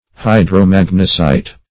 Search Result for " hydromagnesite" : The Collaborative International Dictionary of English v.0.48: Hydromagnesite \Hy`dro*mag"ne*site\ (h[imac]`dr[-o]*m[a^]g"n[-e]*s[imac]t), n. [Hydro-, 1 + magnesite.]
hydromagnesite.mp3